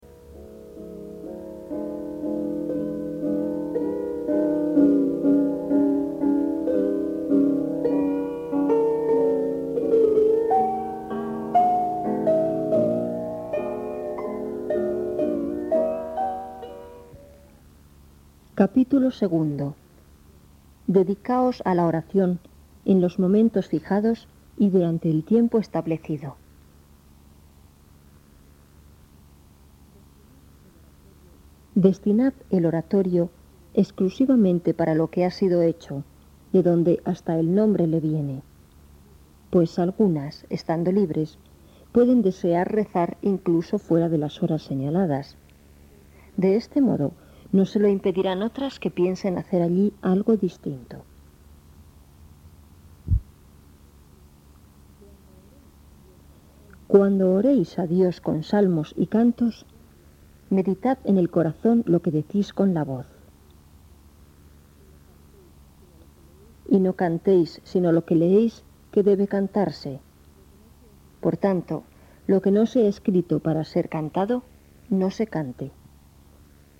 Voz de mujer.